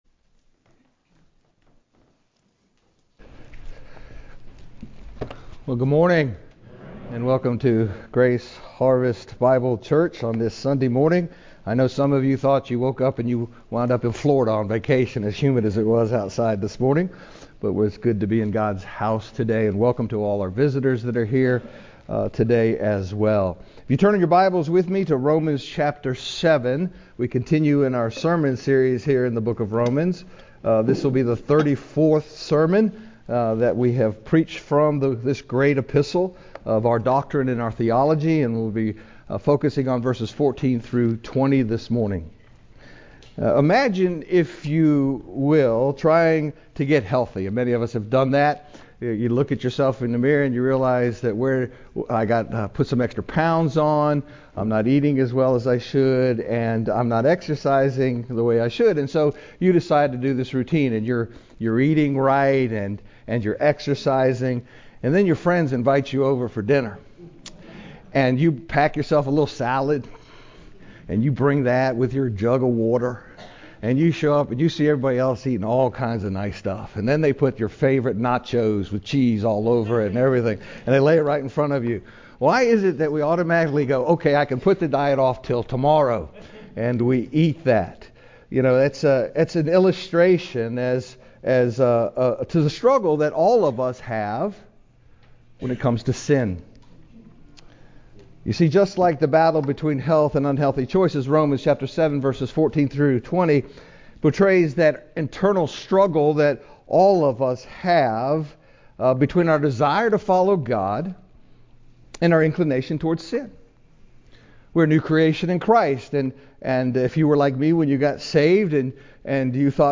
GHBC-Service-Conflicted-Romans-714-20-CD.mp3